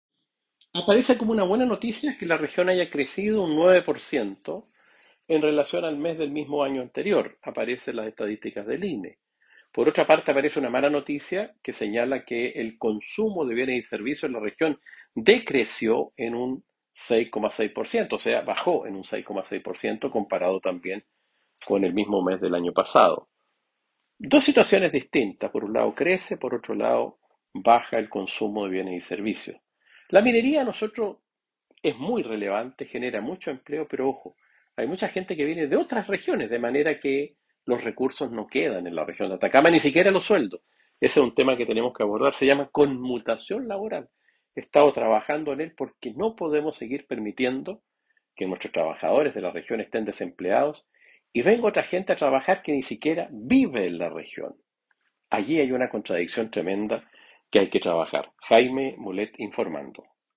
Audio Diputado Jaime Mulet analizando el crecimiento de la región y haciendo hincapié en la conmutación laboral.